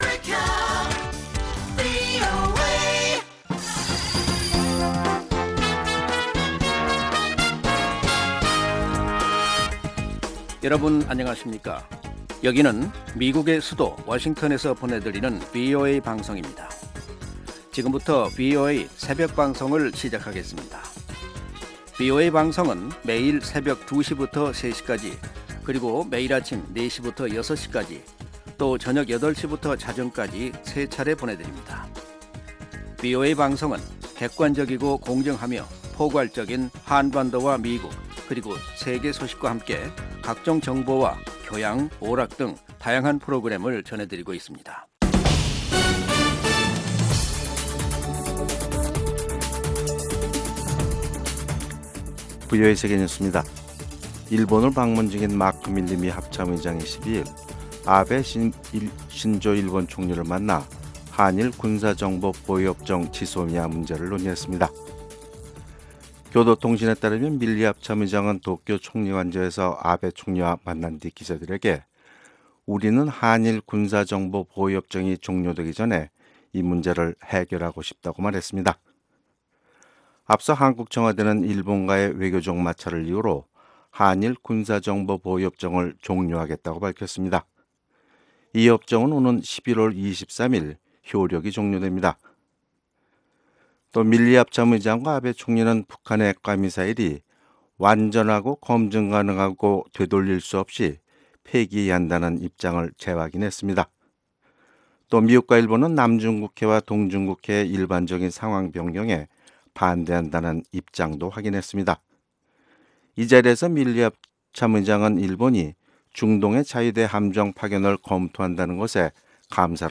VOA 한국어 '출발 뉴스 쇼', 2019년 11월 13일 방송입니다. 마크 밀리 미국 합참의장이 한일군사보호협정 종료는 미한일 삼각 공조 분열을 노리는 중국과 북한에 이익이 된다며 협정 연장을 촉구했습니다. 국제원자력기구 IAEA가 북한의 핵 활동을 우려하며 유엔 안보리 결의 준수를 촉구했습니다.